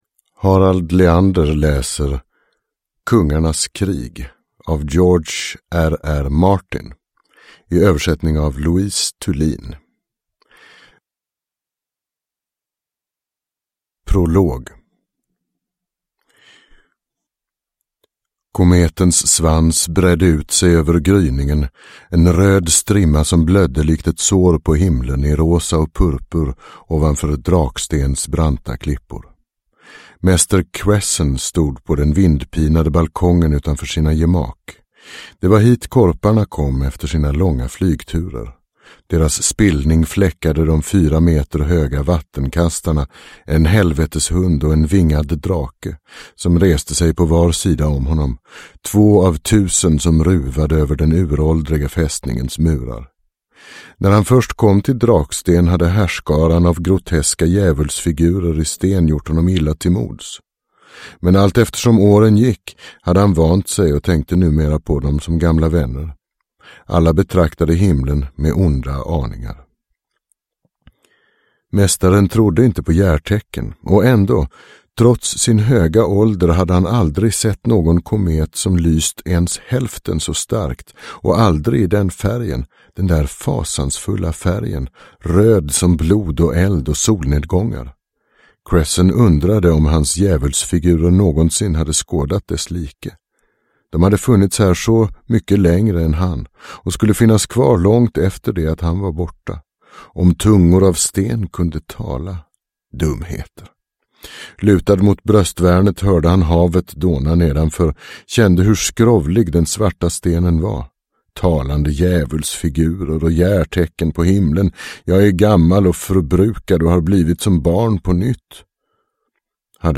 Game of thrones - Kungarnas krig – Ljudbok – Laddas ner